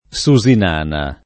Susinana [ S u @ in # na ]